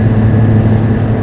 flap.wav